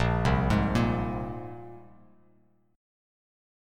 A#7sus2 chord